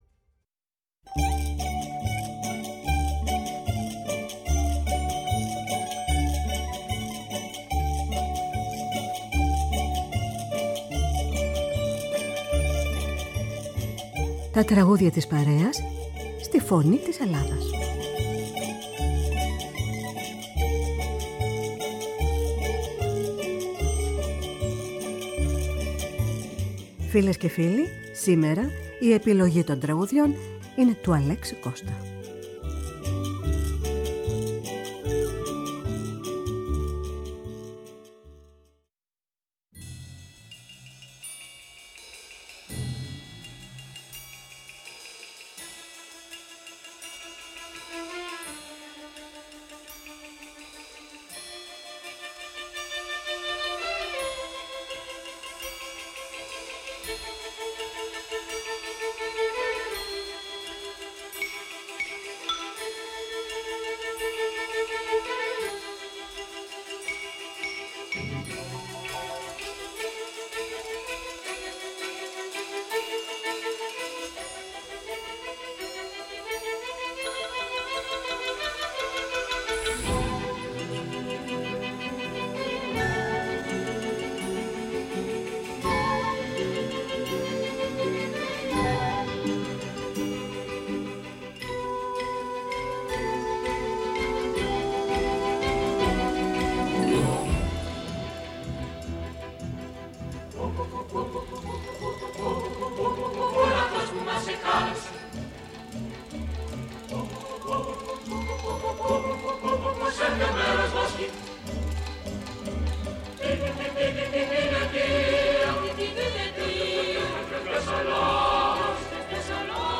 Με μουσικές από την Ελλάδα και τον κόσμο. Η ΦΩΝΗ ΤΗΣ ΕΛΛΑΔΑΣ Τα Τραγουδια της Παρεας στη Φωνη της Ελλαδας Μουσική Τραγουδια της παρεας ΦΩΝΗ ΤΗΣ ΕΛΛΑΔΑΣ